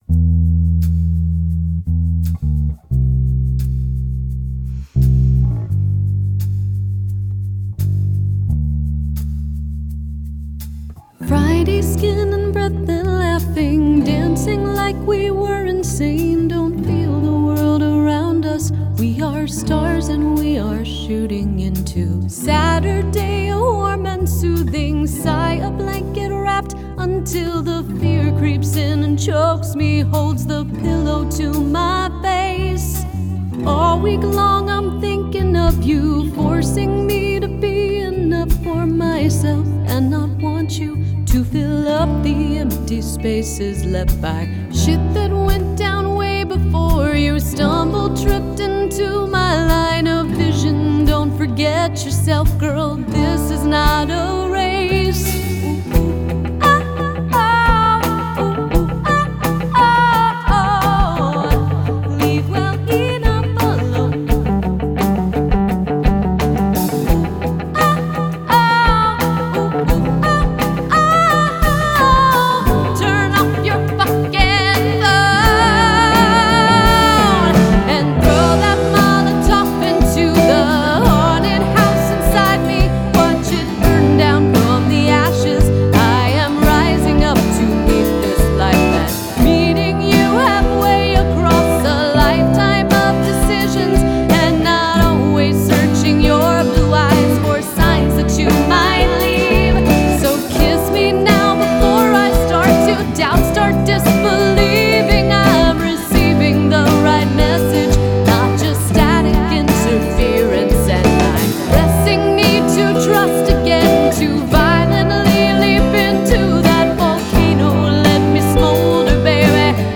Жанр: Pop, Indie Pop, Country, Singer-Songwriter